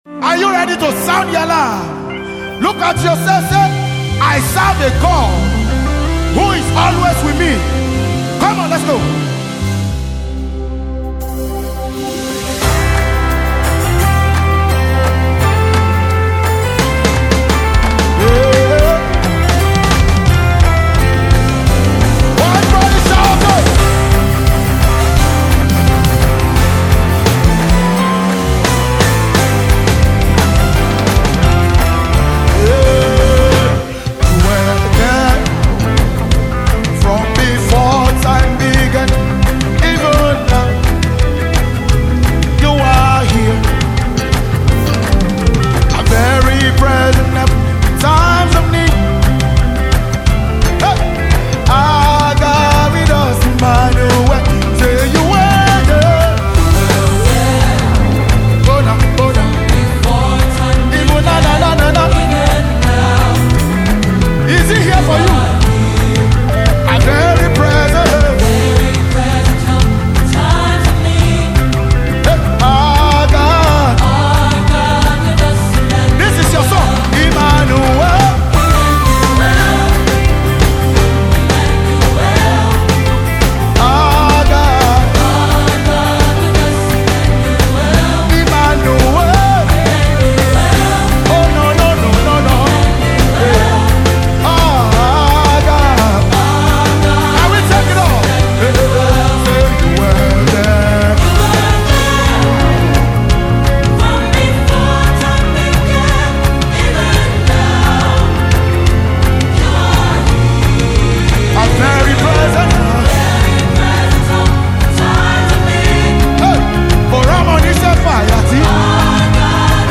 Nigeria Gospel Music
Contemporary Christian music